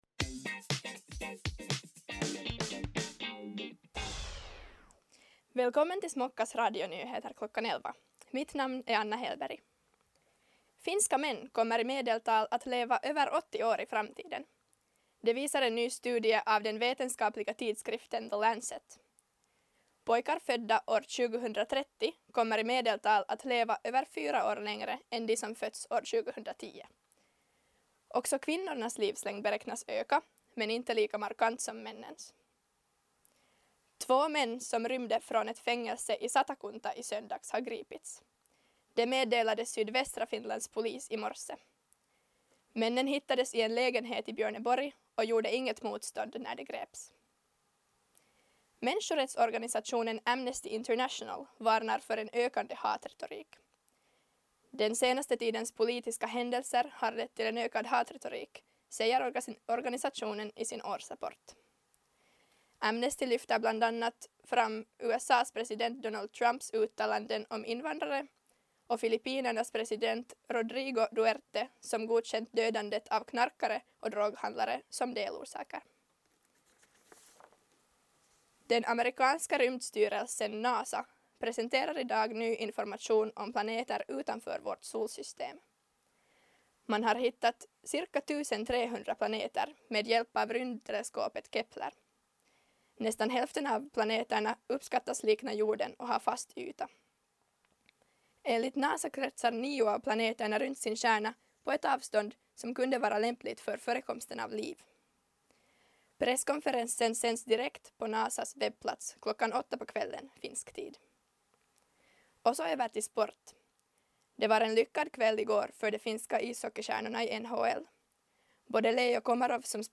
Radionyheter kl. 11